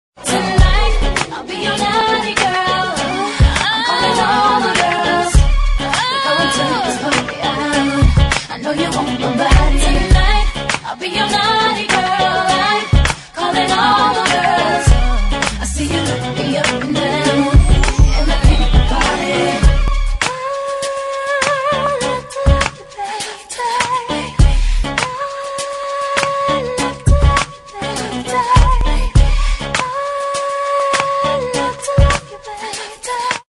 Категория: Попса